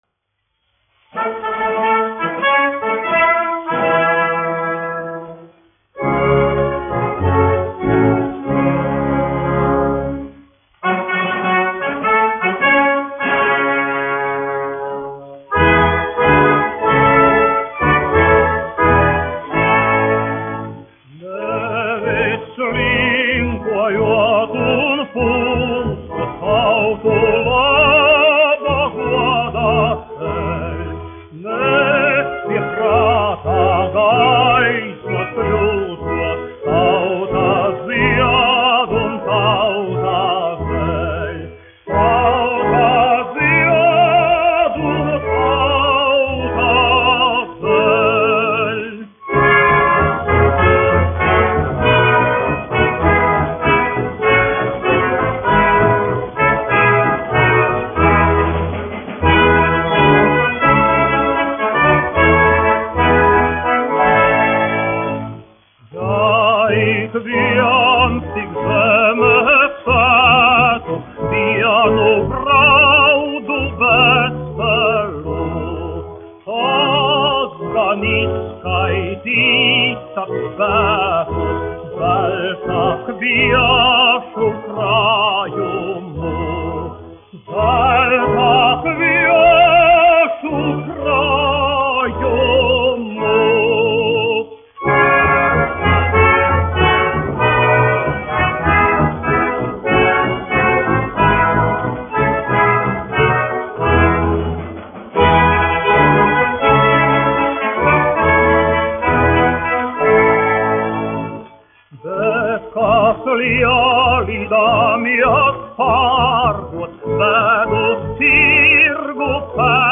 1 skpl. : analogs, 78 apgr/min, mono ; 25 cm
Dziesmas (augsta balss)
Latvijas vēsturiskie šellaka skaņuplašu ieraksti (Kolekcija)